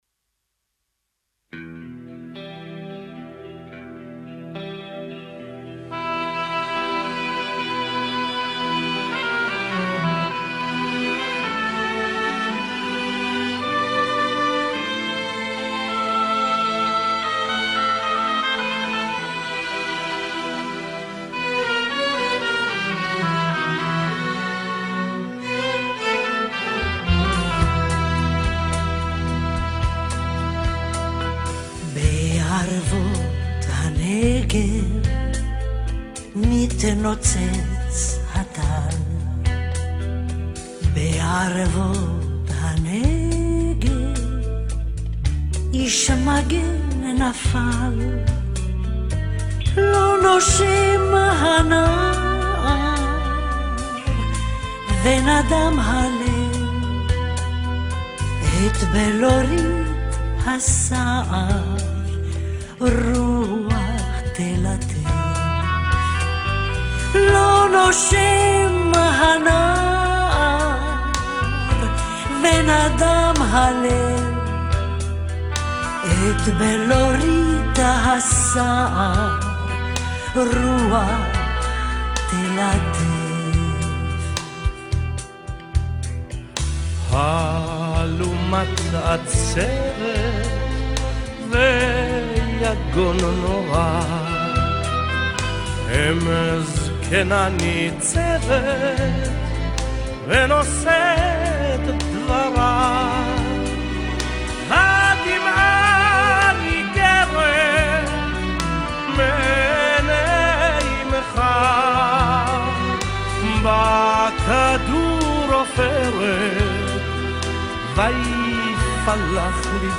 Снято с видео.